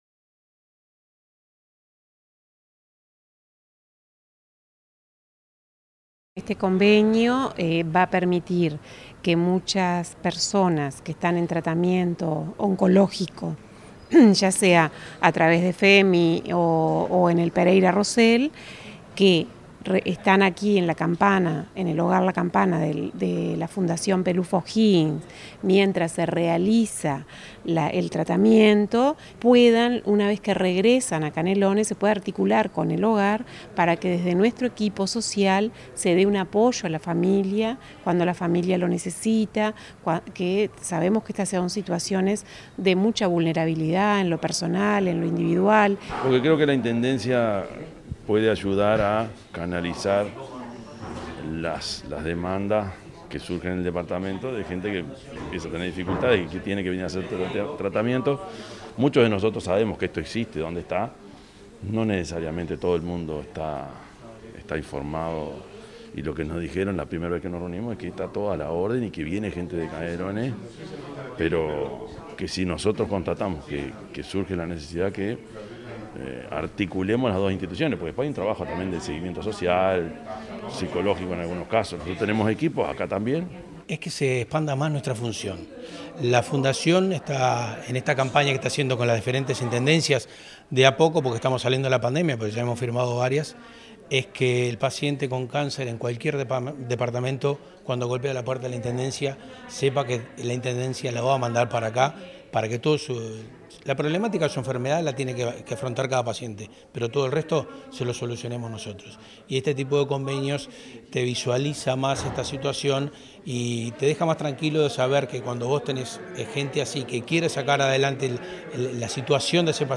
El Intendente de Canelones, Yamandú Orsi, estuvo presente en la firma de convenio realizada en el Hogar La Campana de Montevideo, donde explicó que la Intendencia ayudará a canalizar las demandas que surgen en el departamento de gente que tiene que viajar a la capital del país para hacerse un tratamiento oncológico.